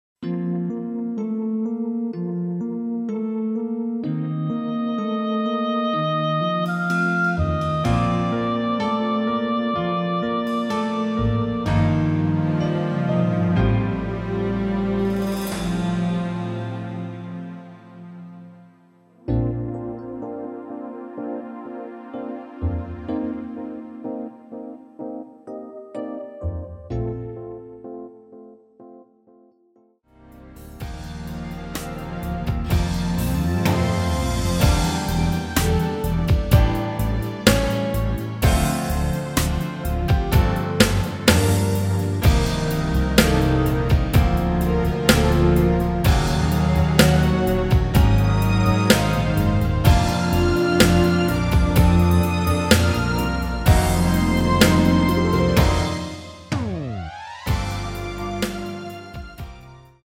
첫번째 듀엣곡
키 Bb
원곡의 보컬 목소리를 MR에 약하게 넣어서 제작한 MR이며